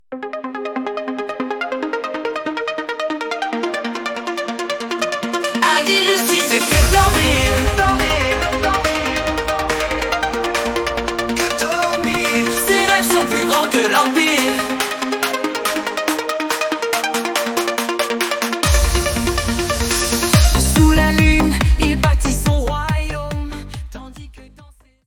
Style : Trance, Eurodance